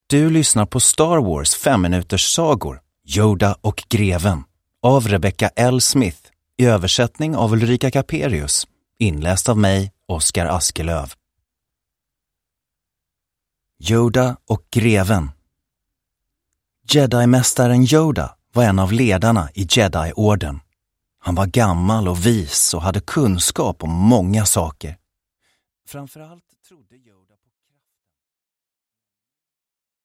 Yoda och greven. Andra berättelsen ur Star Wars 5-minuterssagor – Ljudbok – Laddas ner